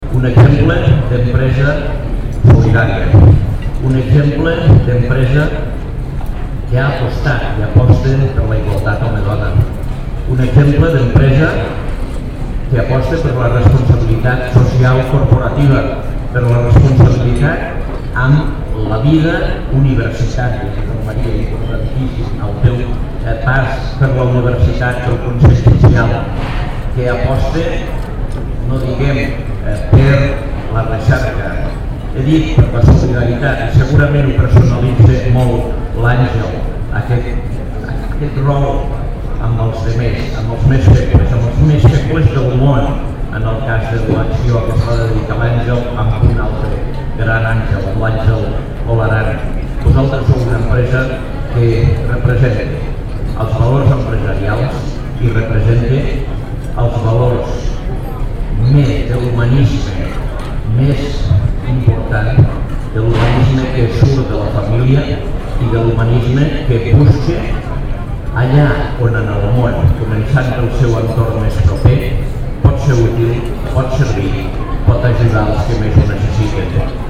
tall-de-veu-de-lalcalde-angel-ros-sobre-la-celebracio-dels-75-anys-de-prefabricats-pujol